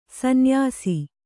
♪ sanyāsi